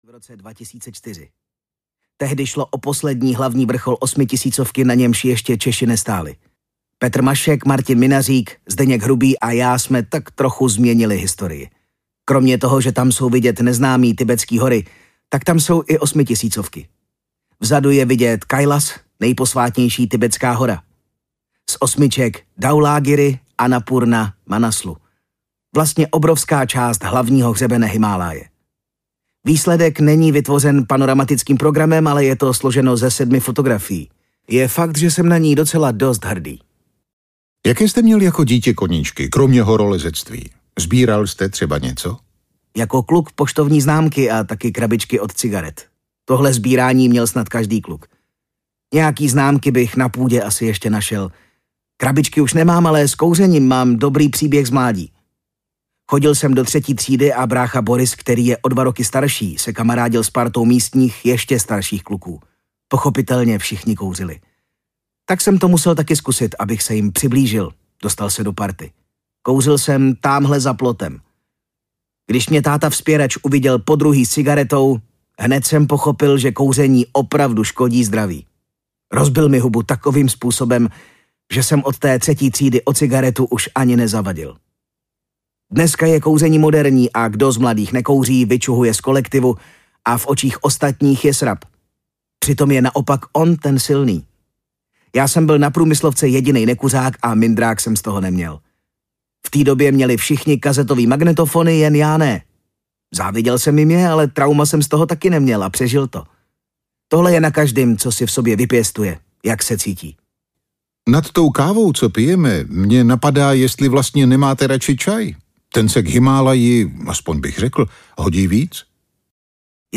Nahoru na horu audiokniha
Ukázka z knihy